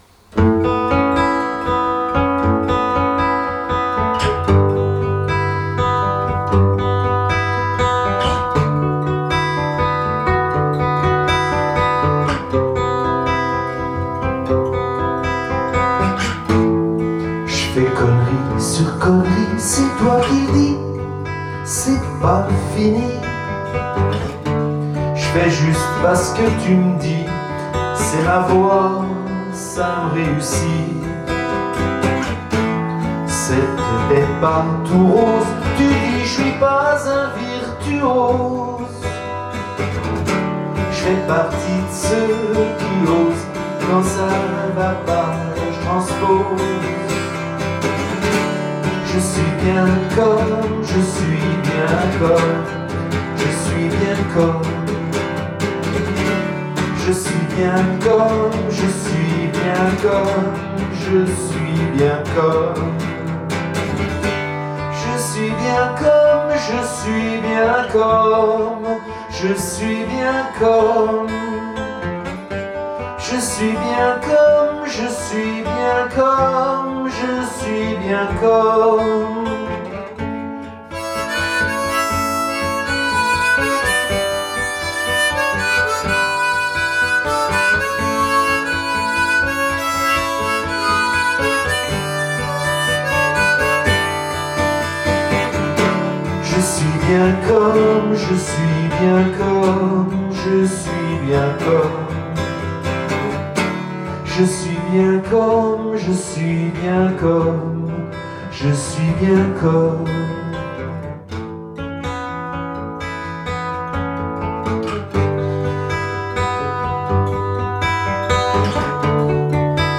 Show danse alternant slowfox et quickstep.